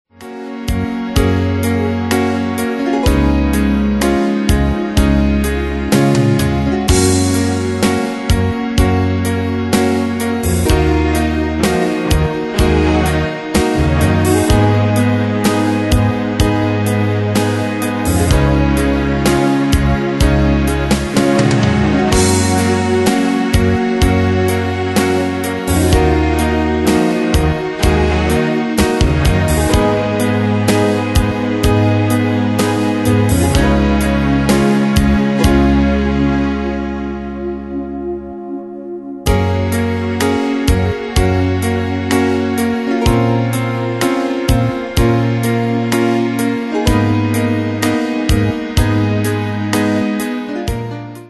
Style: PopAnglo Année/Year: 1993 Tempo: 63 Durée/Time: 3.43
Danse/Dance: Ballad Cat Id.
Pro Backing Tracks